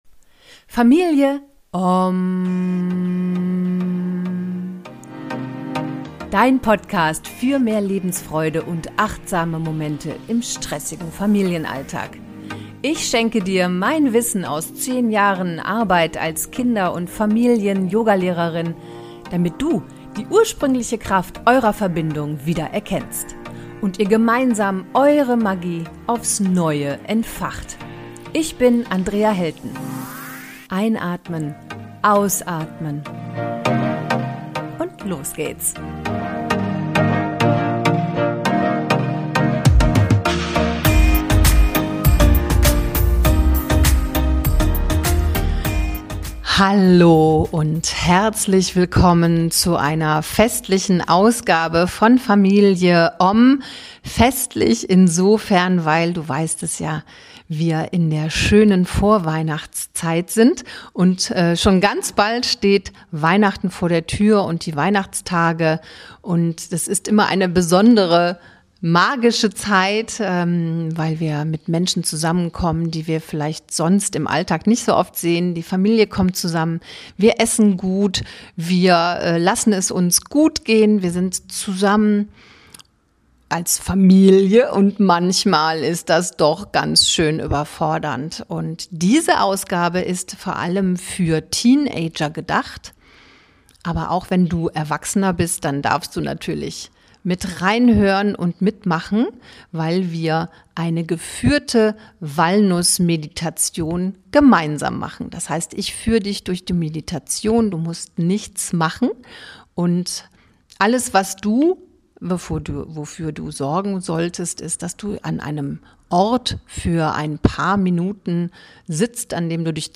#123: Eine kleine Walnuss-Meditation – Achtsamkeit für Teenager (12 min. ohne Musik) ~ Familie Ommm Podcast
Du brauchst nichts weiter als eine Walnuss und ein paar ruhige Minuten. Diese geführte Meditation ist für Teenager gedacht – ohne komplizierte Erklärungen, ohne Esoterik, ohne Leistungsdruck.